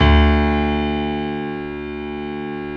55p-pno07-D1.wav